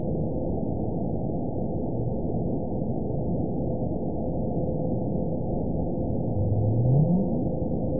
event 917798 date 04/17/23 time 00:31:39 GMT (2 years ago) score 9.58 location TSS-AB01 detected by nrw target species NRW annotations +NRW Spectrogram: Frequency (kHz) vs. Time (s) audio not available .wav